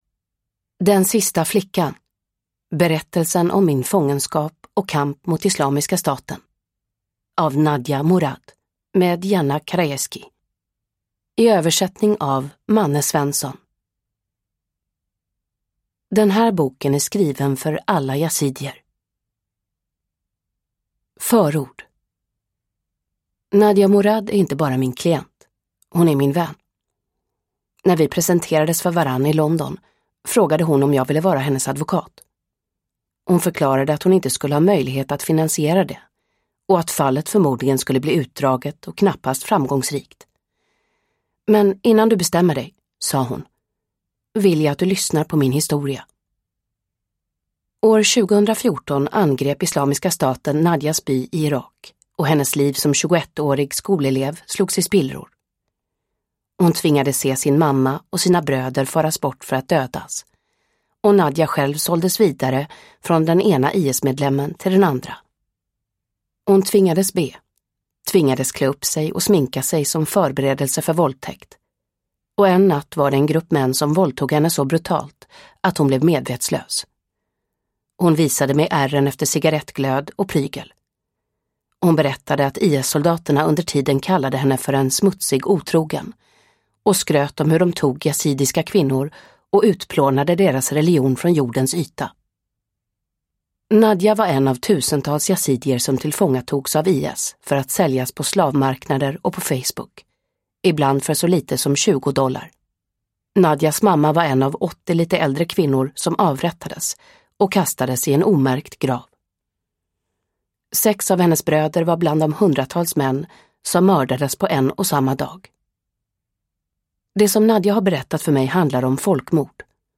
Uppläsare: Mirja Turestedt